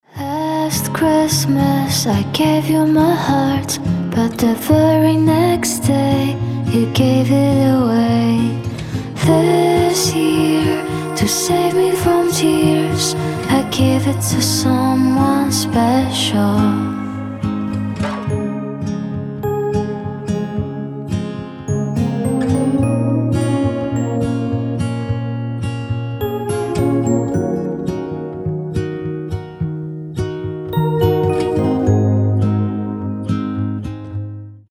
• Качество: 320, Stereo
гитара
спокойные
Cover
красивый женский голос
нежные
рождественские